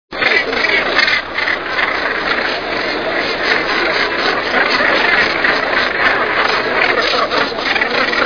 Głuptak - Morus bassanus
głosy